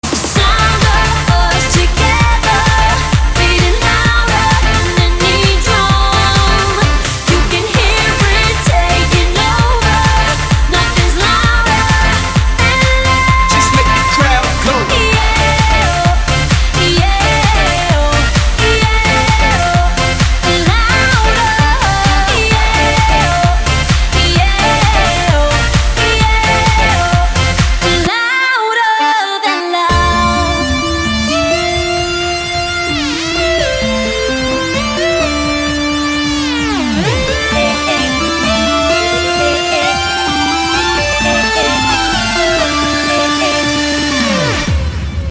• Качество: 256, Stereo
женский вокал
dance
club
vocal